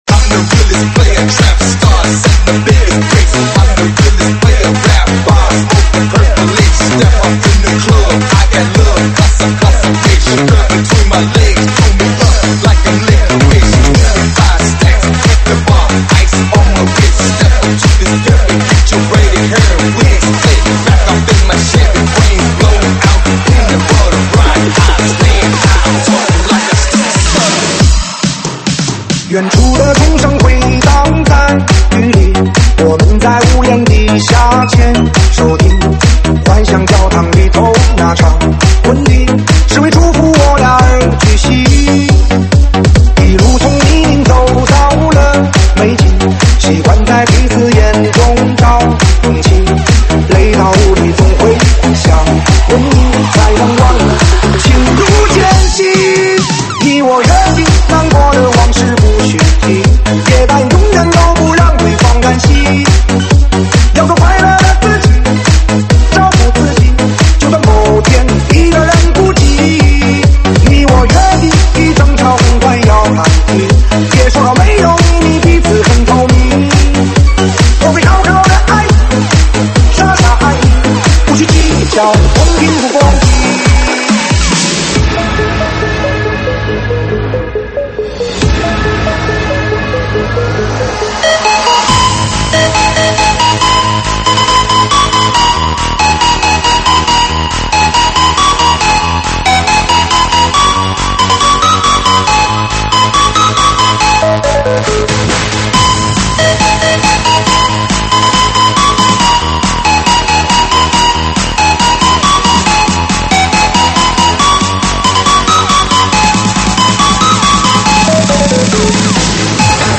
电子Electro
舞曲类别：电子Electro